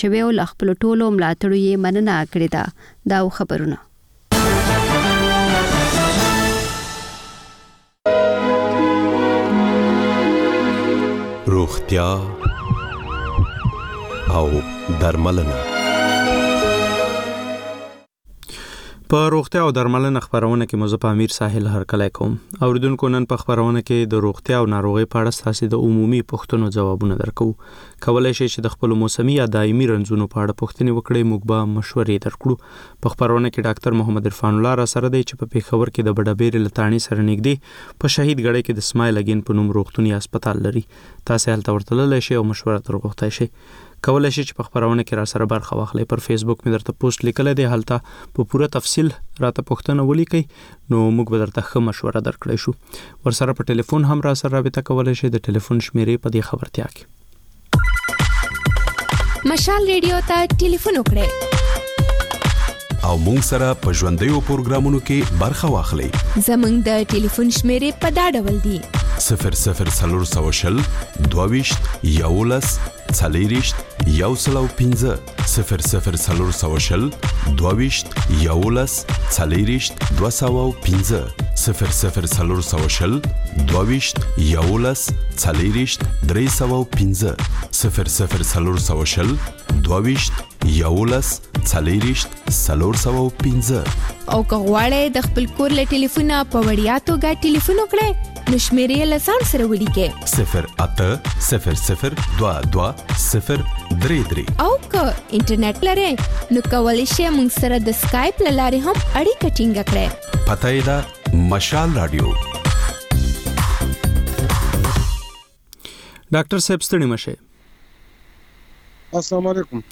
په روغتیا او درملنه خپرونه کې یو ډاکتر د یوې ځانګړې ناروغۍ په اړه د خلکو پوښتنو ته د ټیلي فون له لارې ځواب وايي.